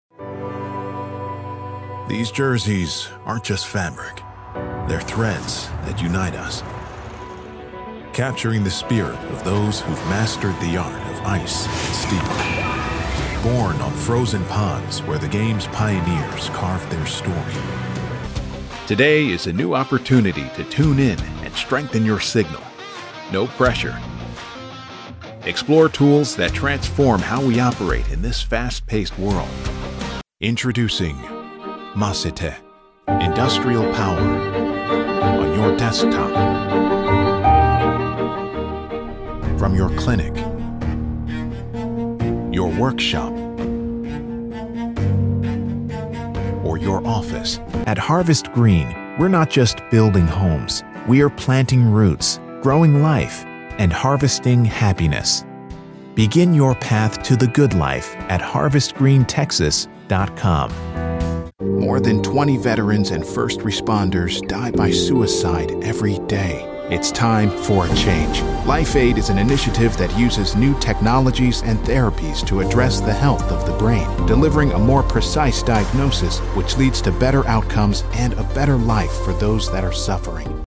Deep, Believable, Natural Male Voice Over Talent
Male
Yng Adult (18-29), Adult (30-50)
It is the friendly deep voice that is believable, powerful, articulate, reassuring, clear and cool.
Main Demo
All our voice actors have professional broadcast quality recording studios.